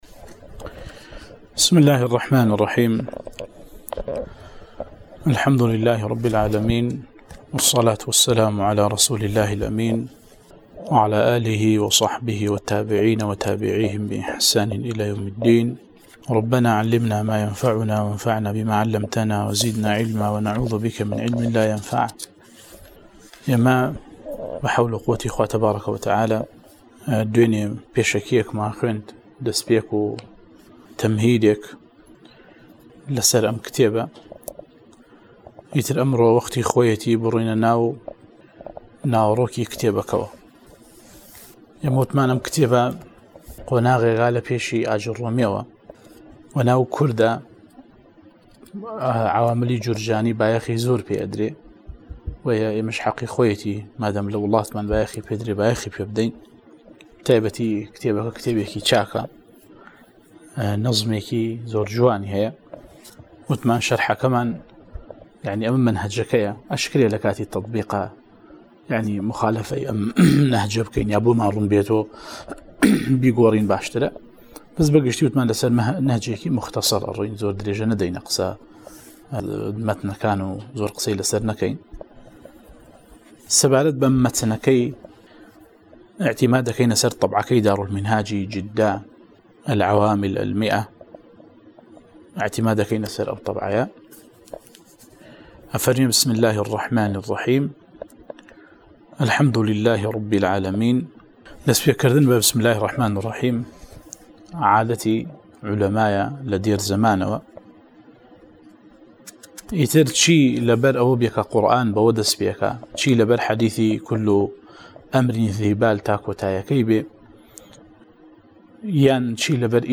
02 ـ شەرحی العوامل المائة، (عوامل الجرجانی) (نوێ) وانەی دەنگی: